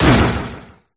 urshifu_ambient.ogg